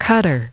Transcription and pronunciation of the word "cutter" in British and American variants.